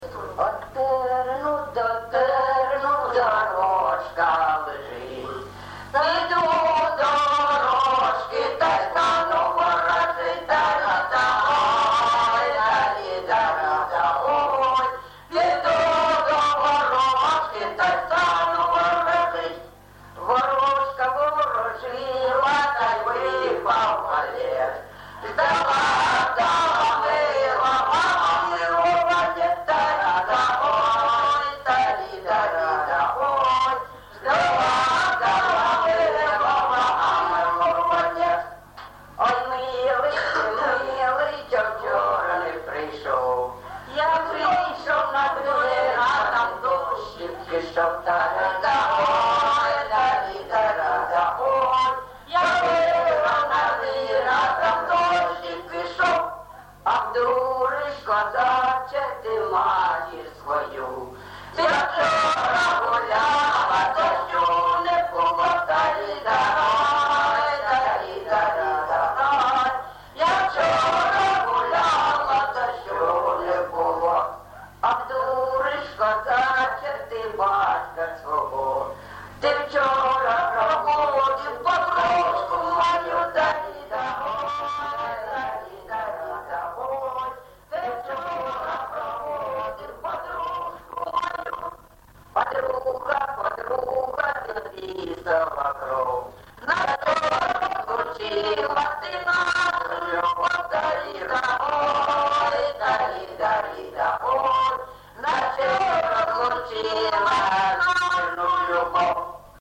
ЖанрСучасні пісні та новотвори